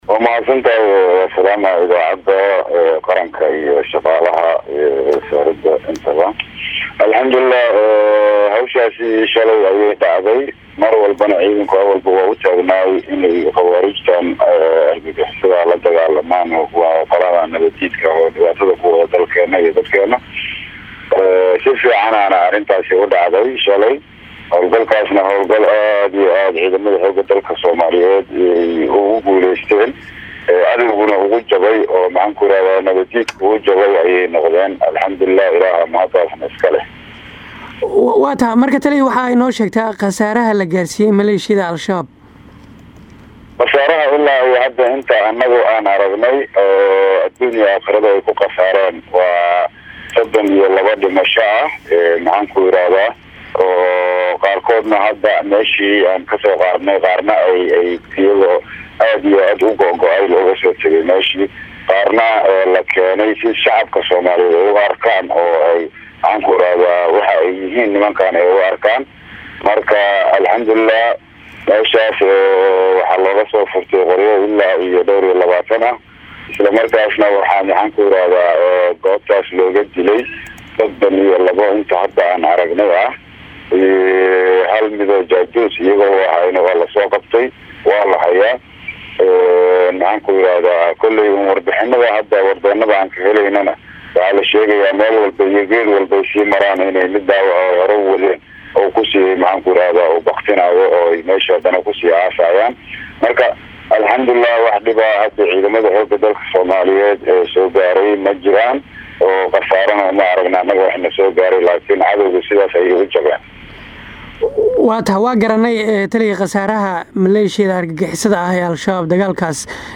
Jeneraal Maxamed Axmed Tareedisho oo ah taliyaha guutada 52 ee Ciidamada xoogga Dalka Soomaaliyeed kuna sugan Gobalka hiiraan ee bartamaha Dalka oo la hadlay Radio Muqdisho Codka Jamhuuriyadda Soomaaliya ayaa sheegay in Dagaal saacado badan qaatay oo shalay ka dhacay deegaano ka tirsan Gobolka Hiiraan lagu dilay 30 horjooge oo ka tirsanaa maleeshiyaadka nabad iyo nolal diidka ah ee Al-shabaab halka gacanta lagu soo dhigay sargaal sare oo maleeshiyaadka ka tirsanaa.